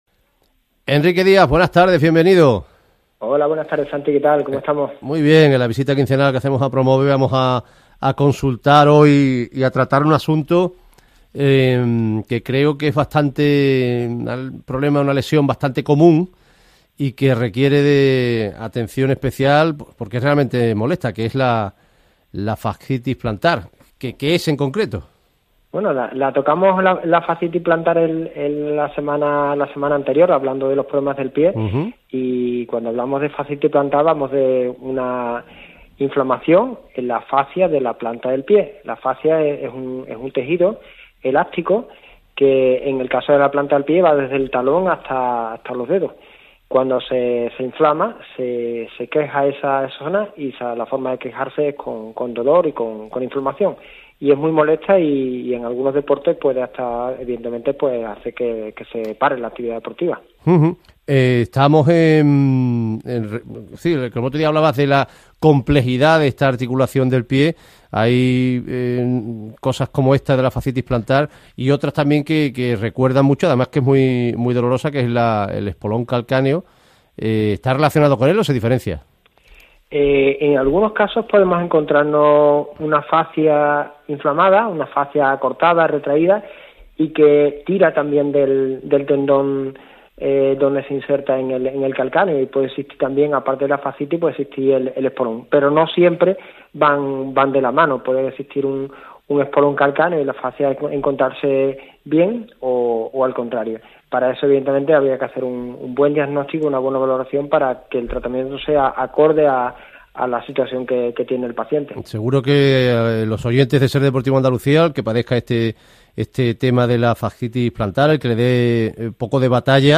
En el programa de radio de esta semana hablaremos sobre la fascitis plantar.
Emitido el domingo 28 de febrero de 2022, en la Cadena SER- Radio Sevilla.